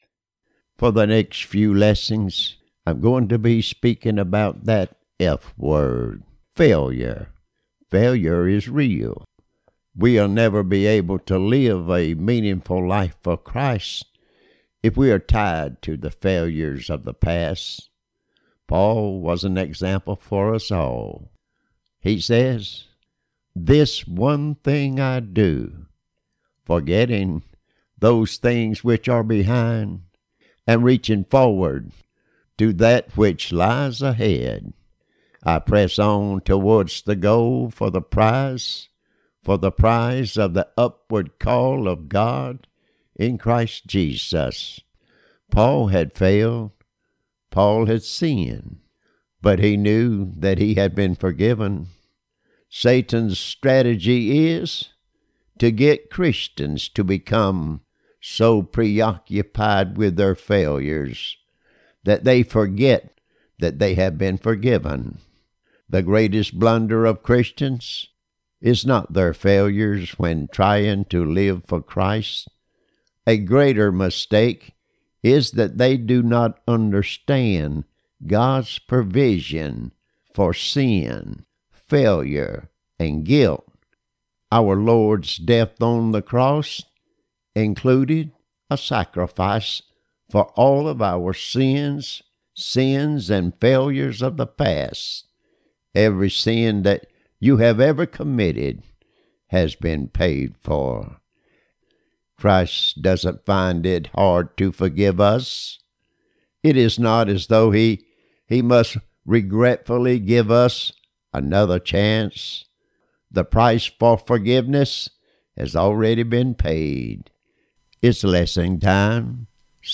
Part one of a four part lesson.